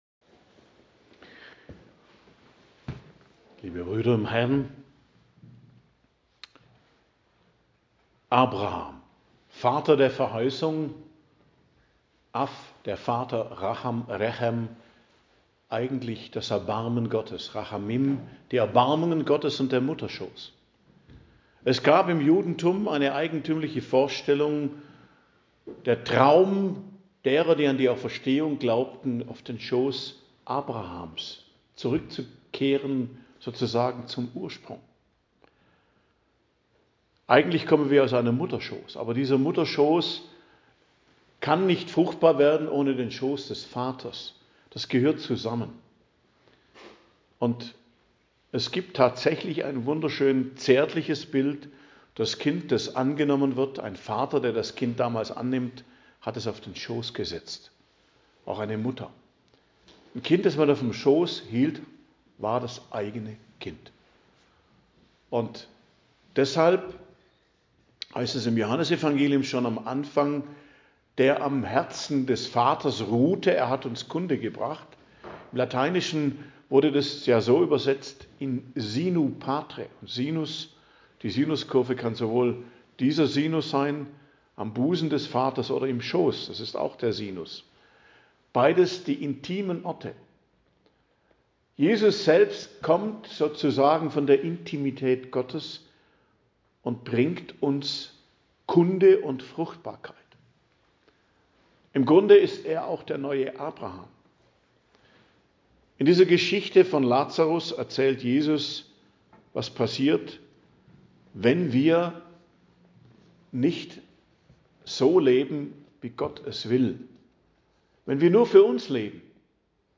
Predigt am Donnerstag der 2. Woche der Fastenzeit, 5.03.2026